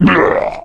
normal zombie die 3.mp3